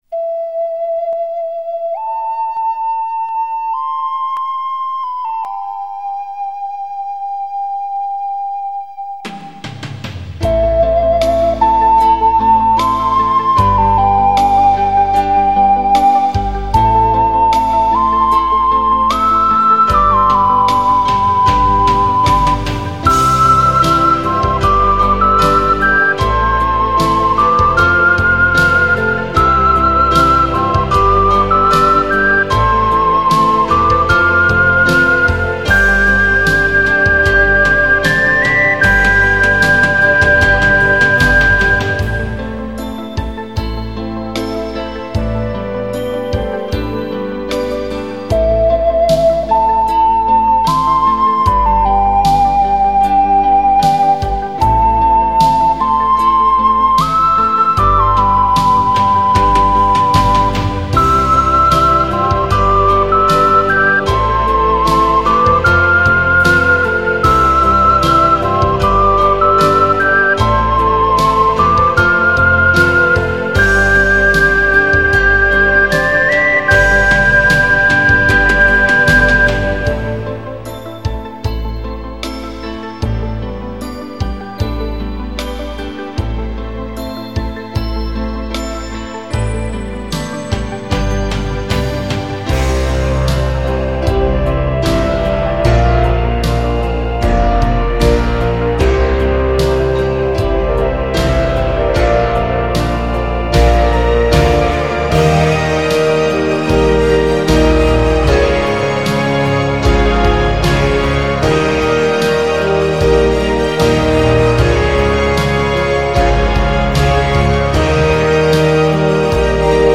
연주곡
오카리나